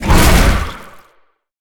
Sfx_creature_hiddencroc_chase_os_03.ogg